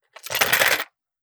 Gun Sold 002.wav